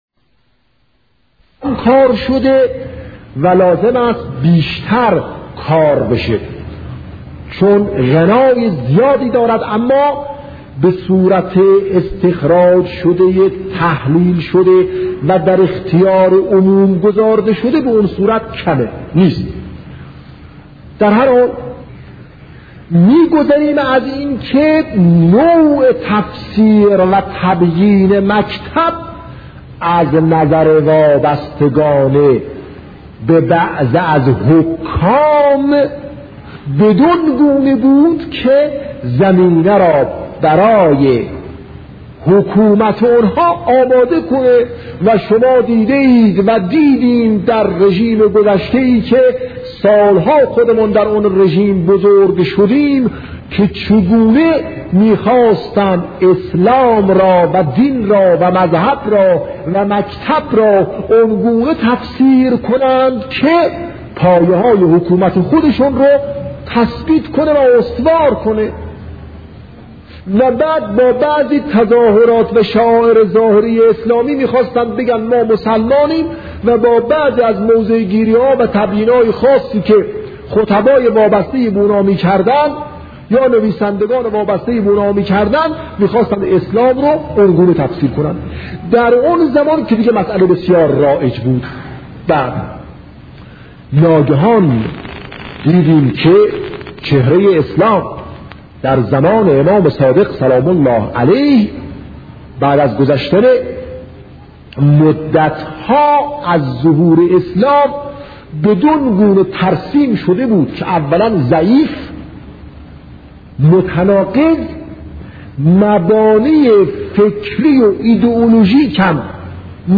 نقش امام صادق (ع) در انقلاب فرهنگی با صدای دلنشین شهید باهنر- بخش‌دوم
بیانات بزرگان